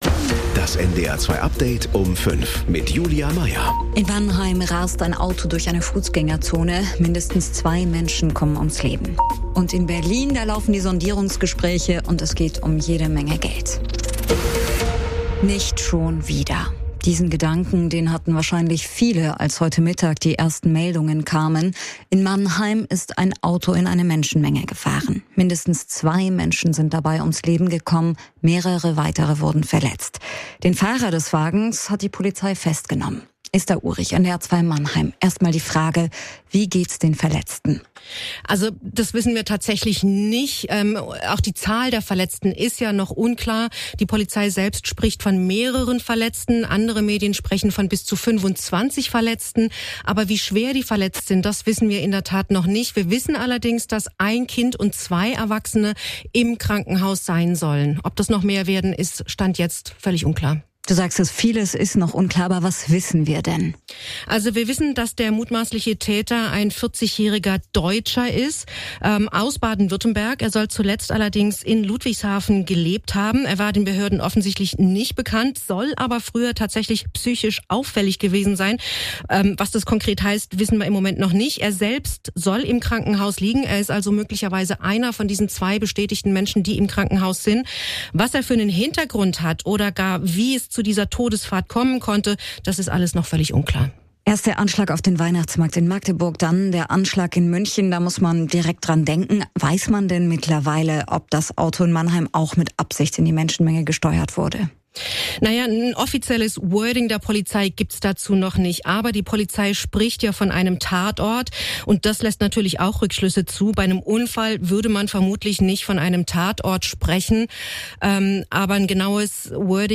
Genres: News